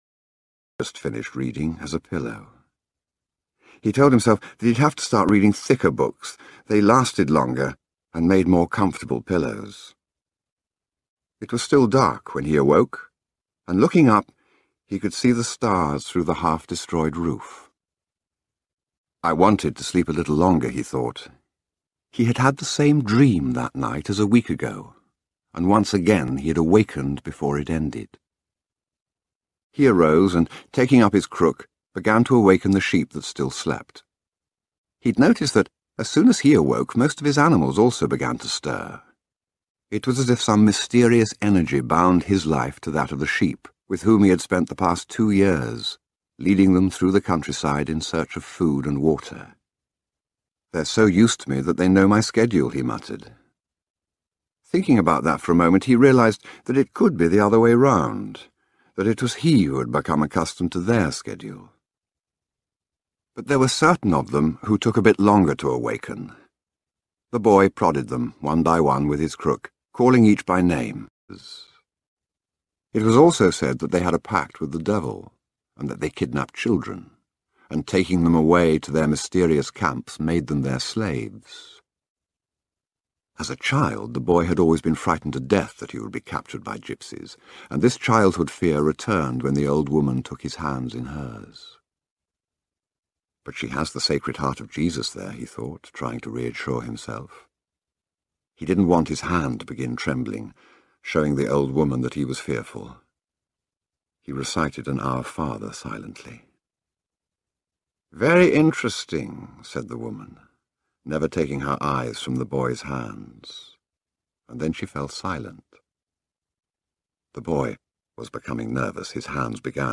The Alchemist full Audio book sound effects free download